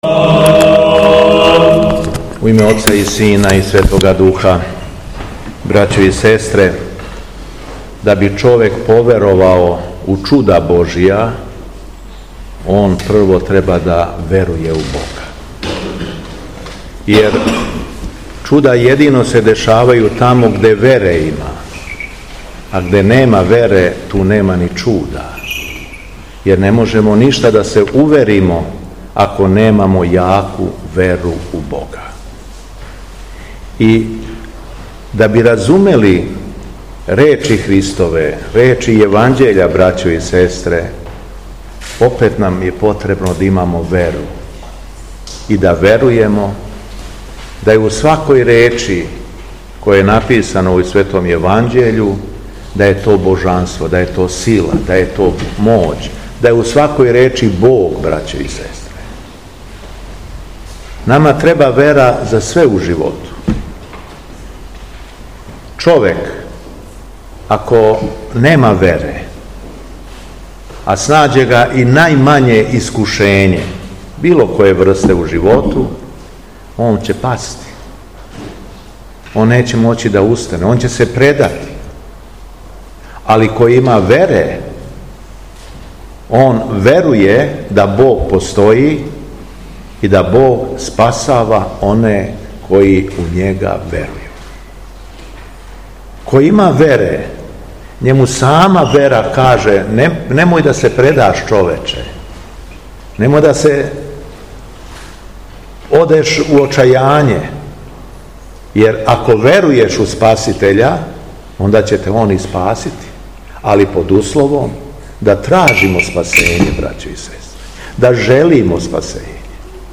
На дан светих мученика Трофима, Теофила и других са њима, Његово Преосвештенство Епископ шумадисјки Г. Јован, слижио је у суботу, 5. августа 2023. године, свету Архијерејску Литургију у храму Светог Николаја, у селу Сибница код Сопота.
Беседа Његовог Преосвештенства Епископа шумадијског г. Јована